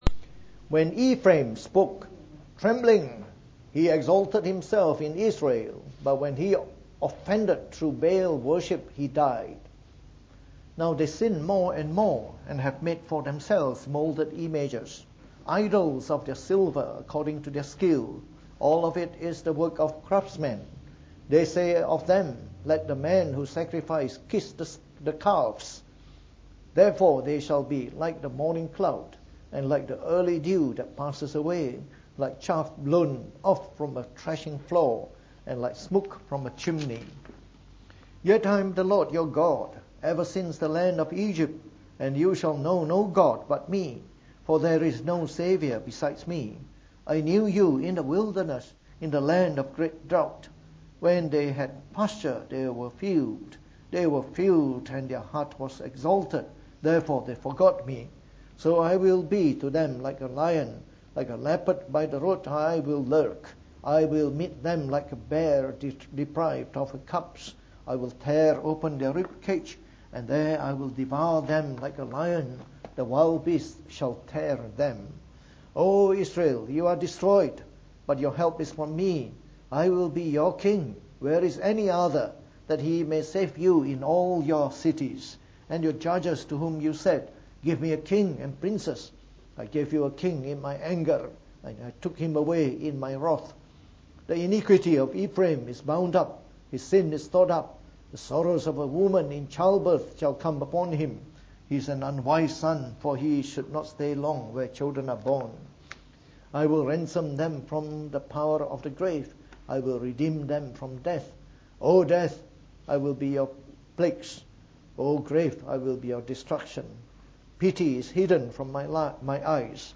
From our series on the Book of Hosea delivered in the Morning Service.